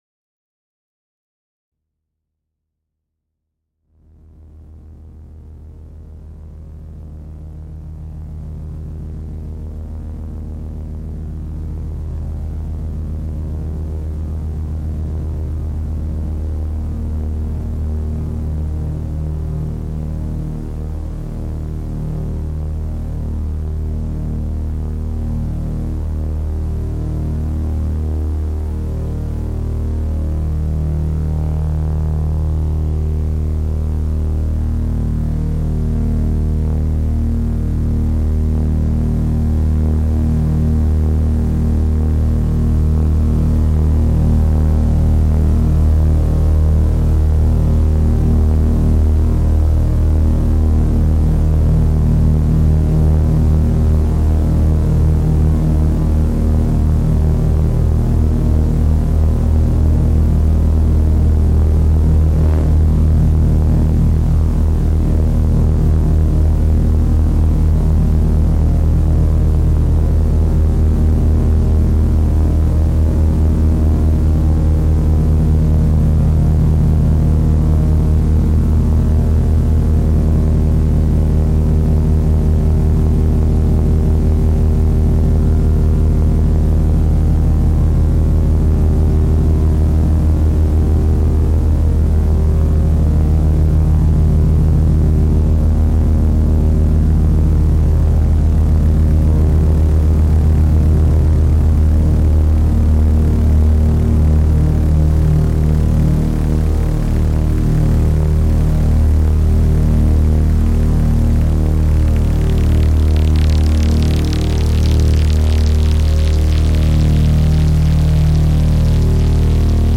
The drone thread, share and discuss
I love these things with digitone, but anyway, giving a go with two voices of rytm… hope it’s the right way :wink: